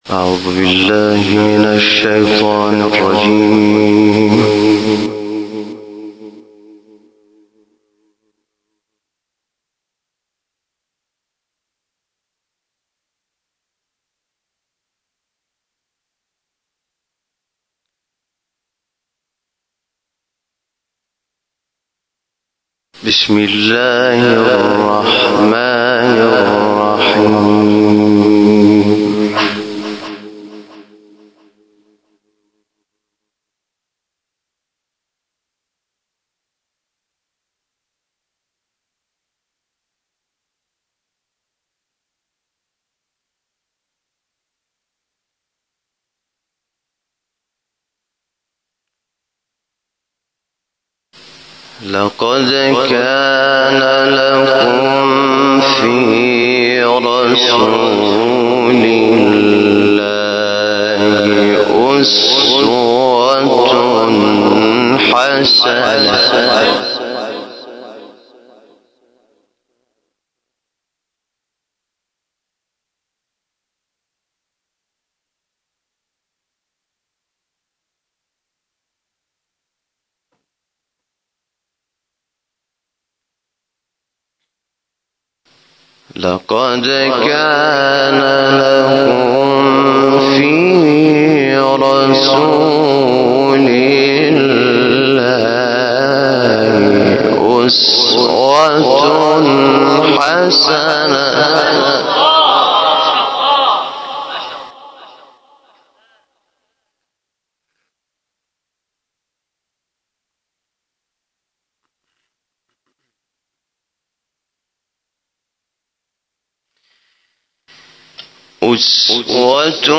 گروه شبکه اجتماعی: حامد شاکرنژاد شب‌های دهه سوم ماه صفر را در حسینیه بنی الزهراء(س) تهران به تلاوت آیاتی از کلام‌الله مجید می‌پردازد.